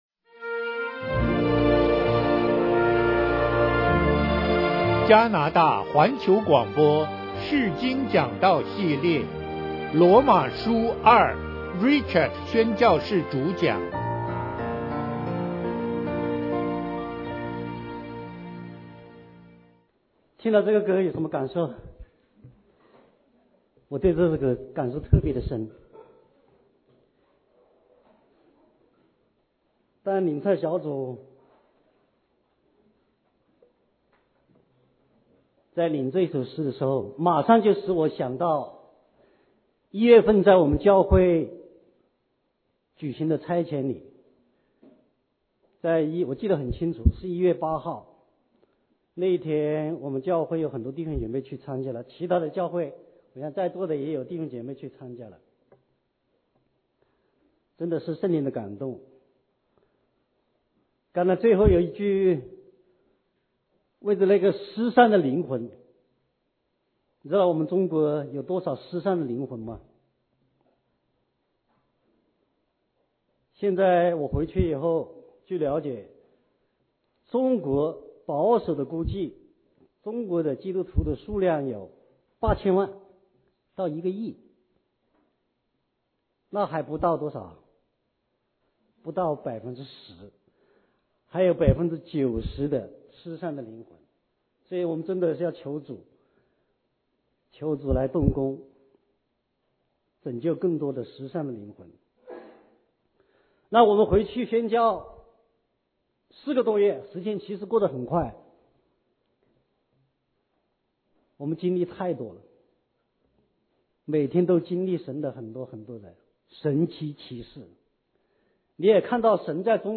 講座錄音 羅馬書二 第一堂 羅馬書二 第二堂 羅馬書二 第三堂 羅馬書二 第四堂 講義大綱 羅馬書二 (大綱) 釋經講道系列 – 羅馬書二